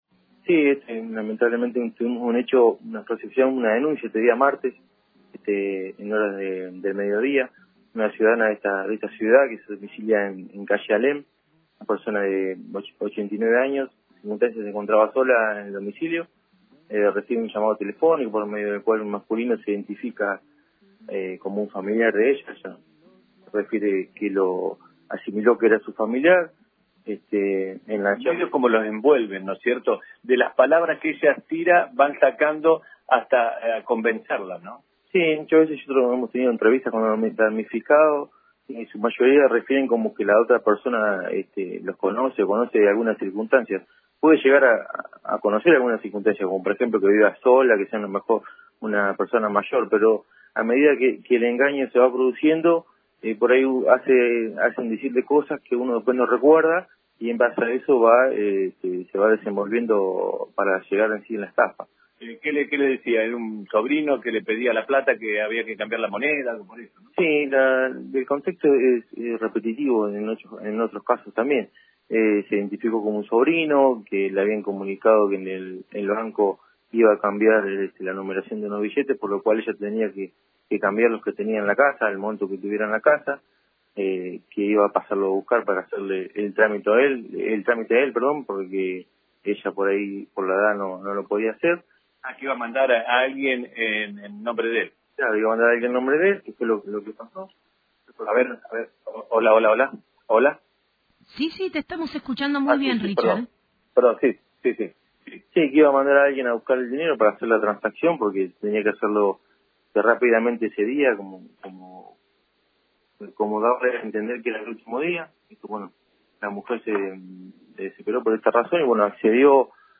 A continuación los informes  del subcomisario: